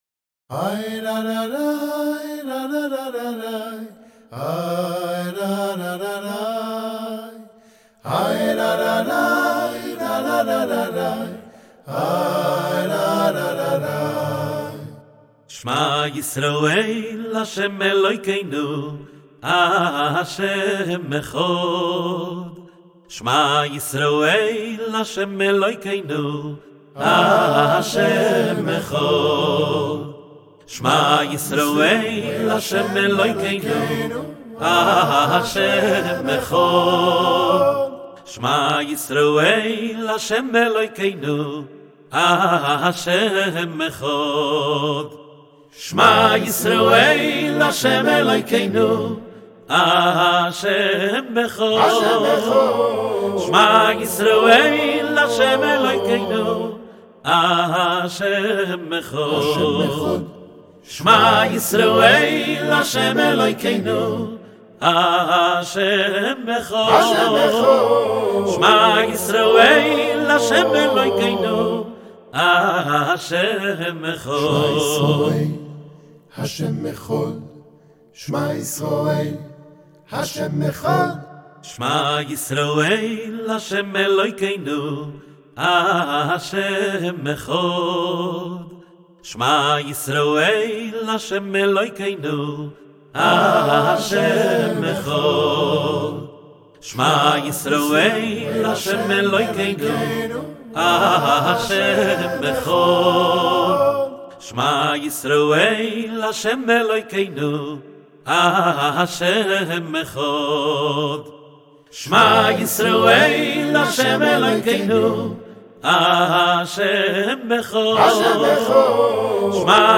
לקראת השלושים של כ"ק אדמור מקאליב זצ''ל השיר של הרבי זצ"ל בביצוע ווקאלי
וברגע של ספונטניות הם הקליטו יחד את שירו של האדמו"ר מקאליב זצ"ל